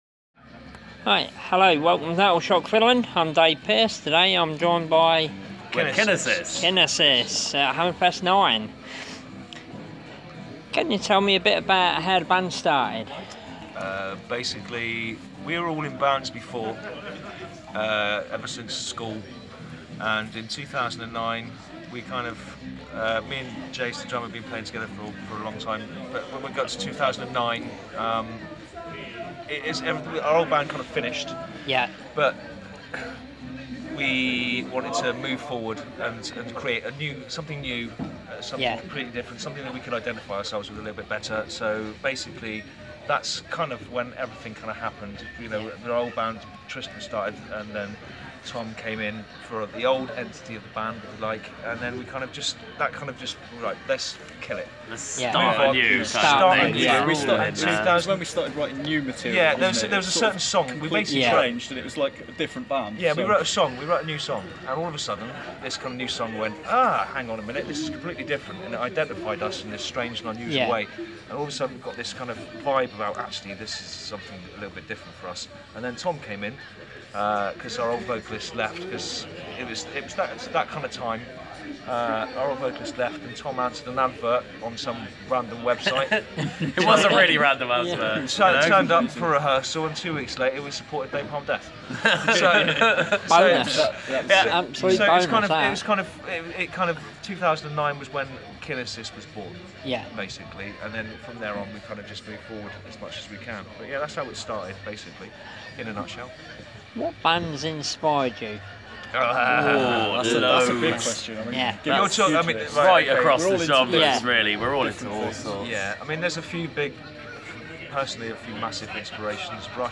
Interview With KINASIS At Hammerfest IX